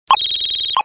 ui_receive_money.wav